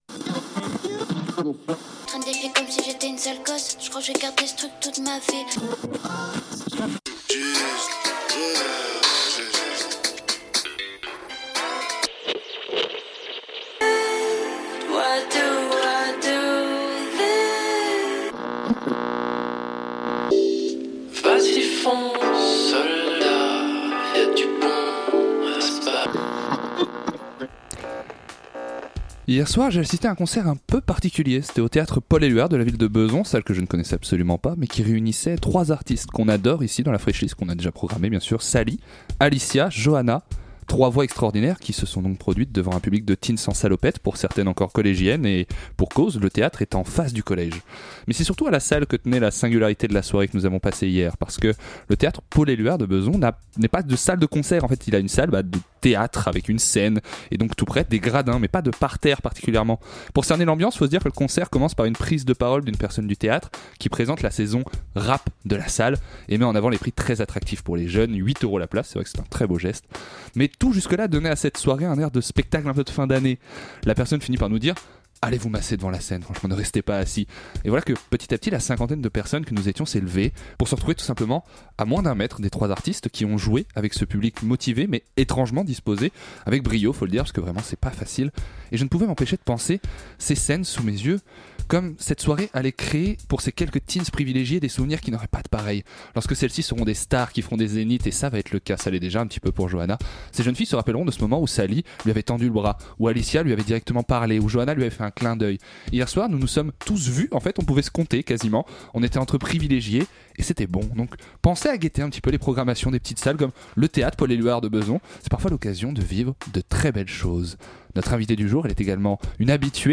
Cette saison, la Fraîche Liste met à l'honneur les artistes qu'elle diffuse dans une émission mensuelle faite de découvertes, de confessions et de musique. Les trois programmateurs.rices vous invitent à faire plus ample connaissance avec l'une des voix qui les a sédui.te.s ce mois-ci, et à découvrir la sélection mensuelle plus en profondeur.